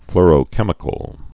(flrō-kĕmĭ-kəl, flôr-)